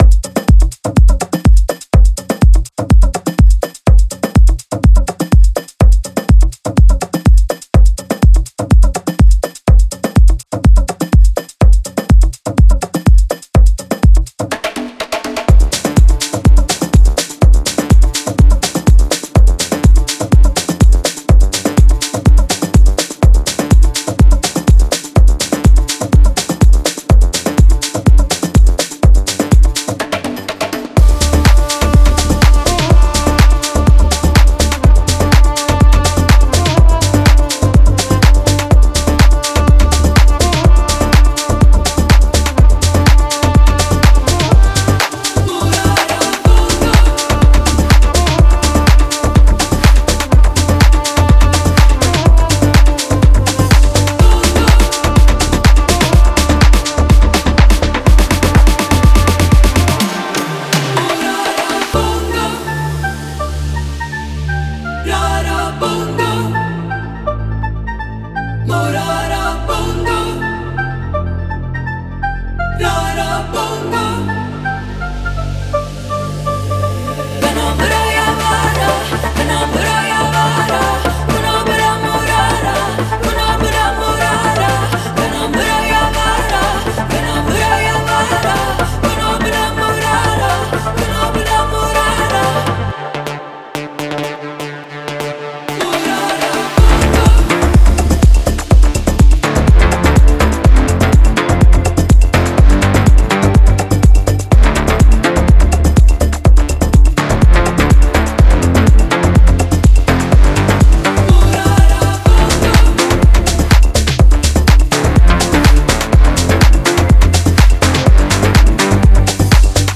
• Жанр: Dance, House